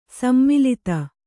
♪ sammilita